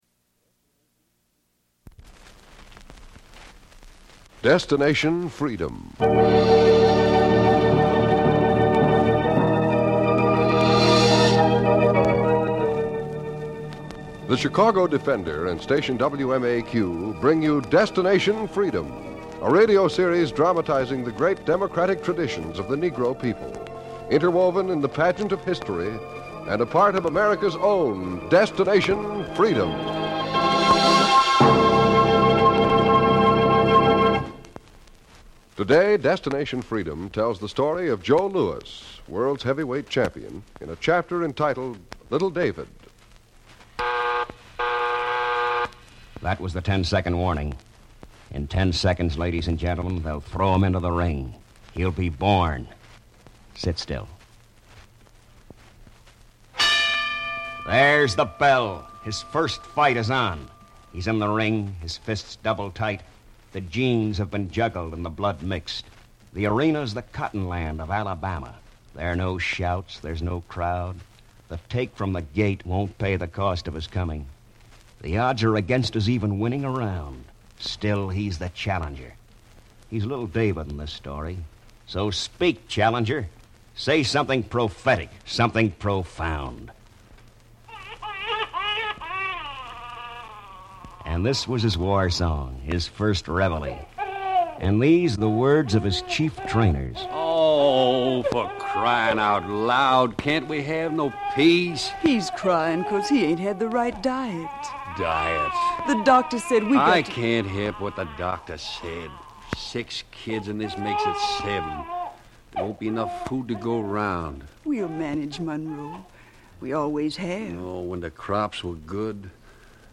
"Destination Freedom" was a groundbreaking radio series written by Richard Durham that aired from 1948 to 1950. The series aimed to highlight the achievements and struggles of African Americans, often focusing on historical figures and events that were underrepresented in mainstream media.